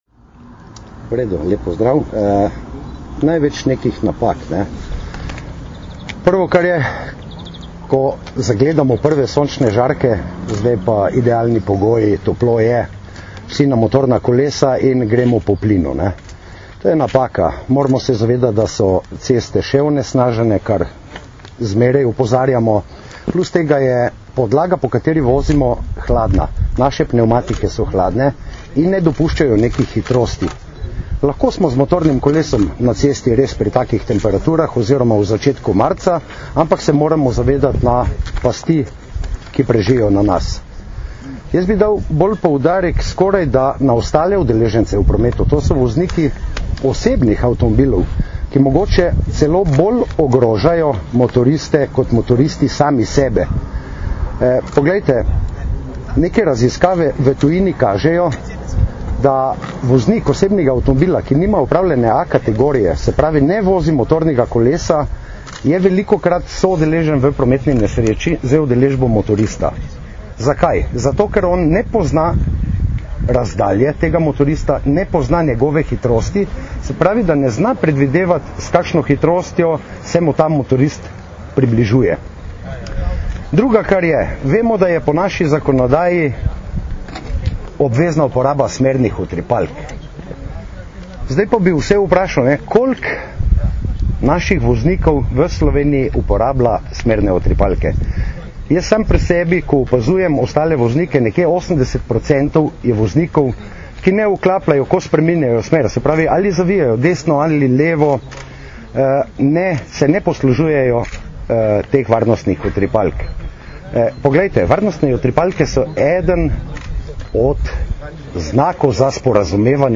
Naj bo motoristična sezona prijetna in varna - informacija z novinarske konference
Zvočni posnetek izjave